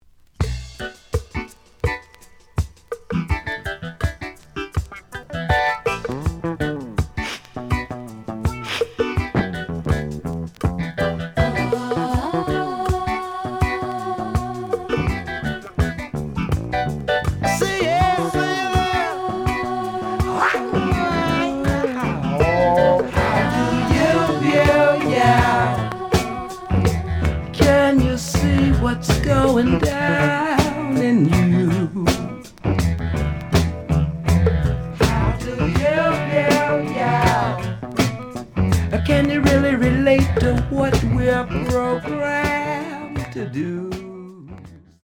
The audio sample is recorded from the actual item.
●Format: 7 inch
●Genre: Funk, 70's Funk